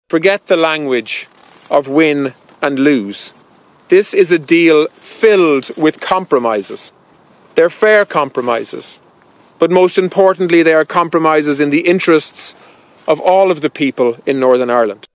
Tanaiste Simon Coveney says the deal is reasonable for all sides: